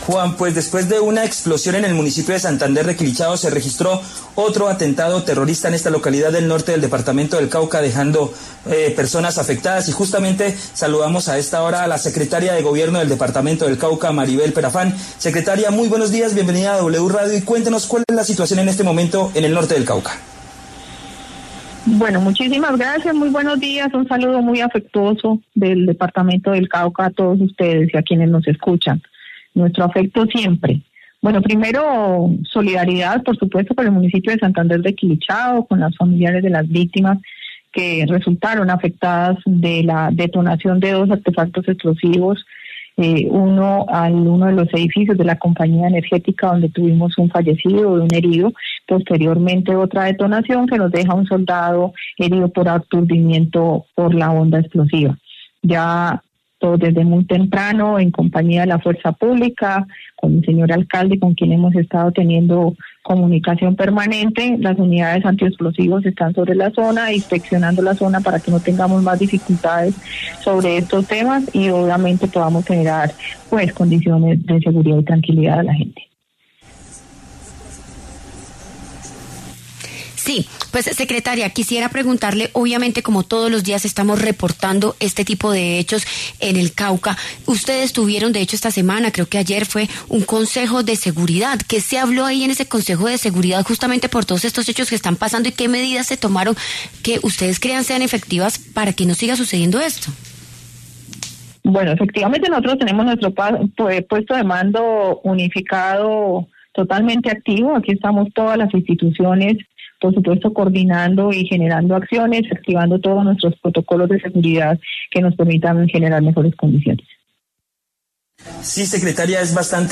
La secretaria de Gobierno del Cauca, Maribel Perafán, en entrevista con W Radio, afirmó que se están tomando las medidas necesarias para generar tranquilidad en la población.